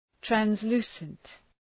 Shkrimi fonetik{trænz’lu:sənt, træns’lu:sənt}